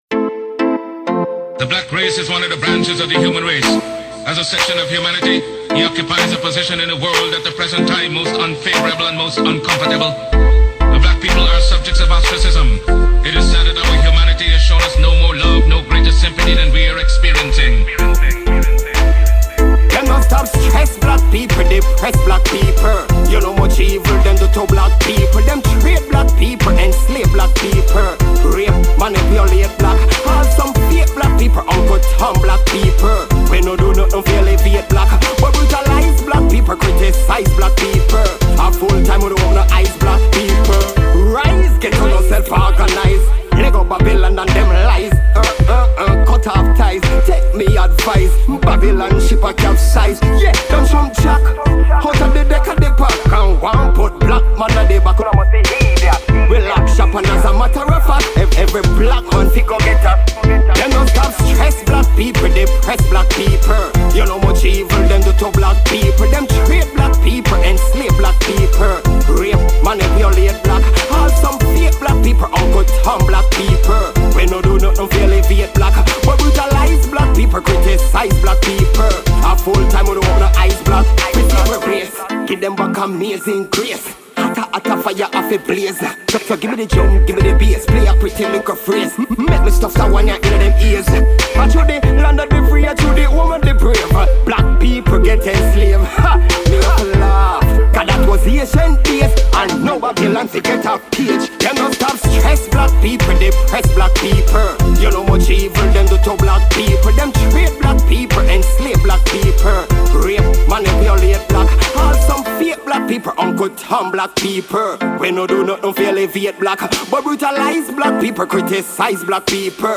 dancehall
roots reggae , uplifting